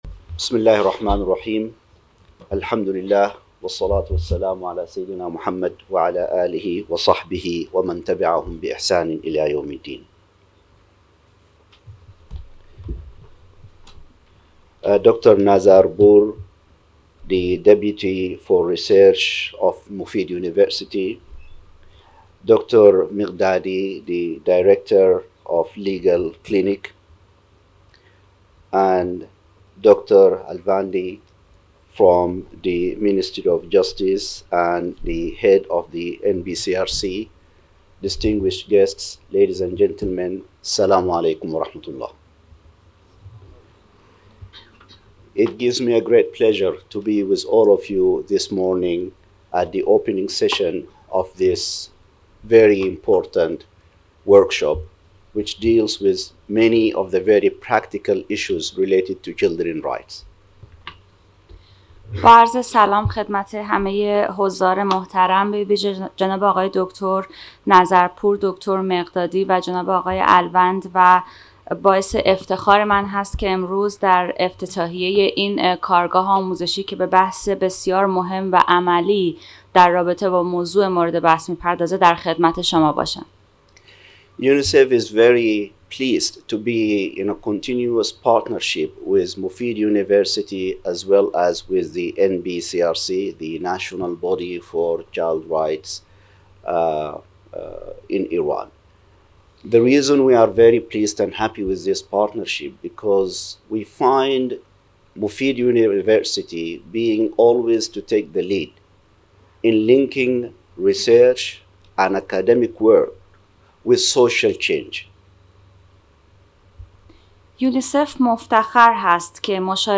این سخنرانی در سال ۱۳۹۲ به زبان انگلیسی و همراه با ترجمه فارسی ایراد شده است.